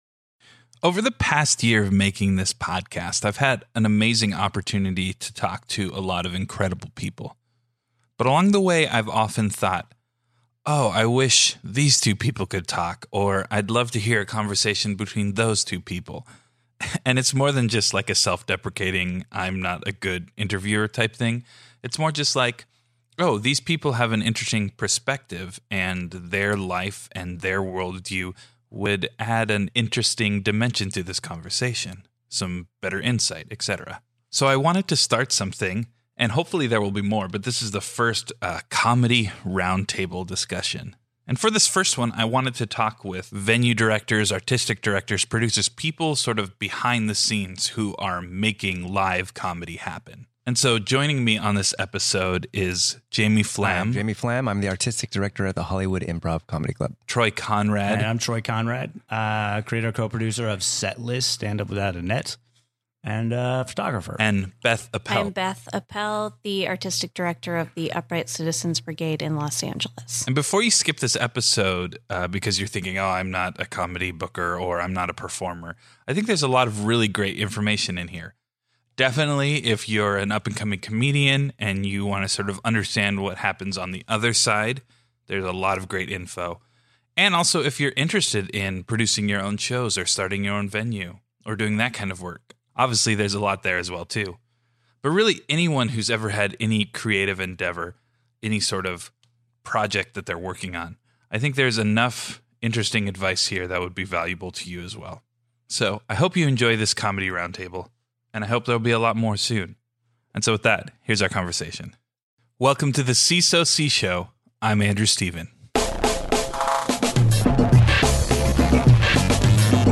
#51 Comedy Roundtable